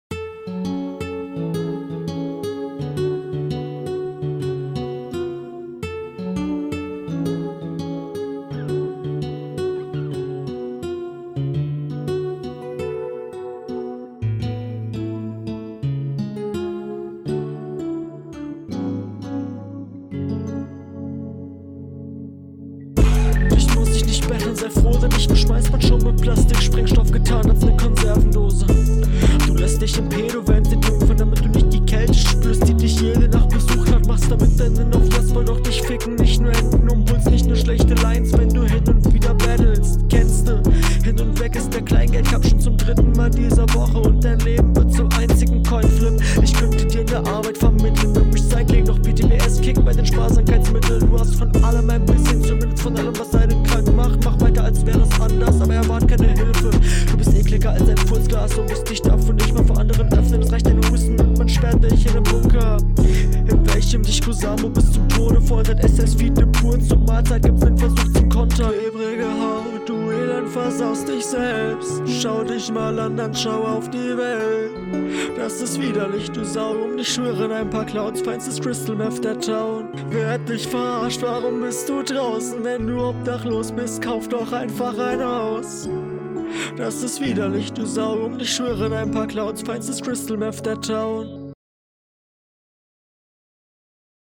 Du hast deine Stimme sehr leise abgemischt. Es fällt mir sehr schwer, dich zu verstehen.